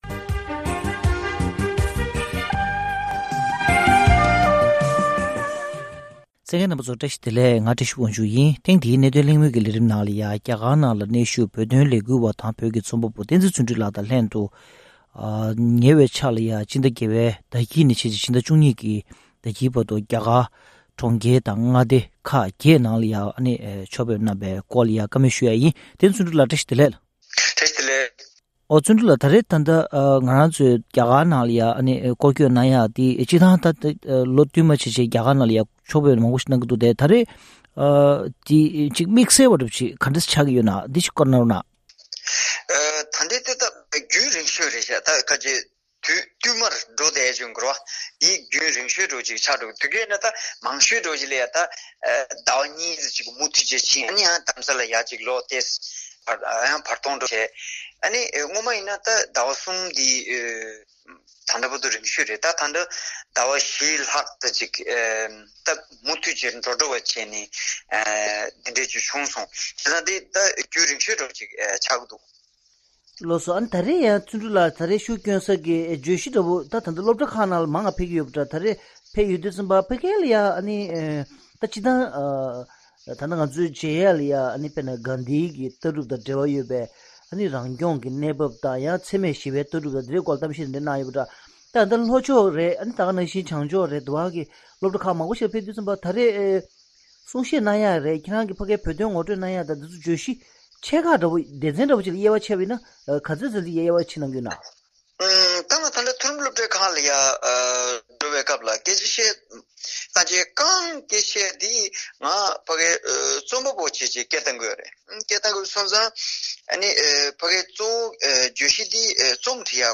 ༄༅༎ཐེངས་འདིའི་གནད་དོན་གླེང་མོལ་གྱི་ལས་རིམ་ནང་།